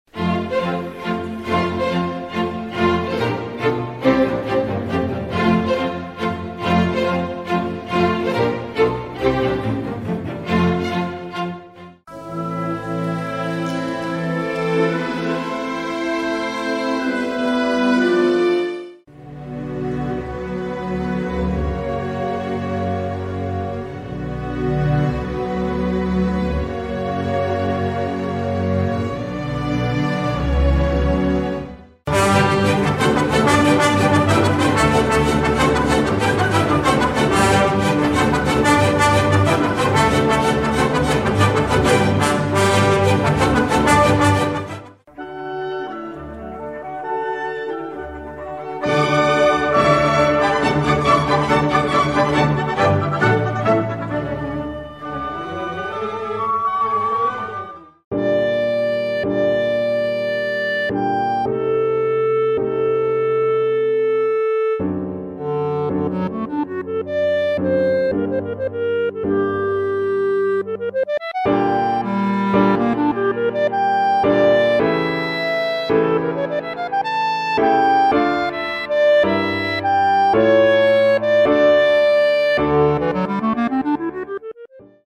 Can you hear the difference between major and minor? Major isn’t always happy and minor isn’t always sad, but it can be a helpful tool.